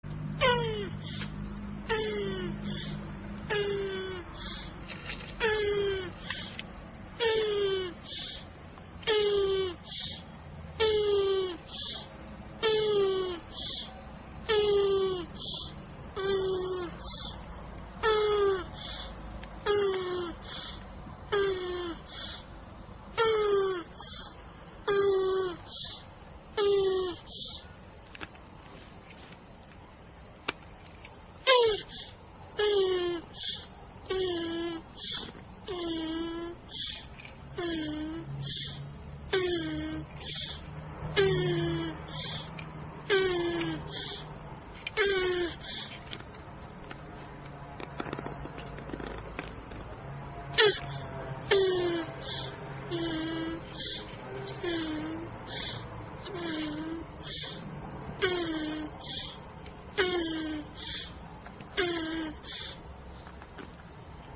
Звук спаривания черепах (для научных целей)